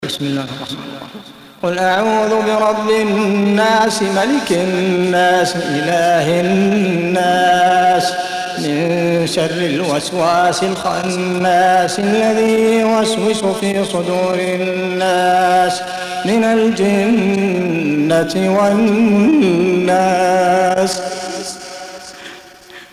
Hafs for Assem حفص عن عاصم
Tarteel المرتّلة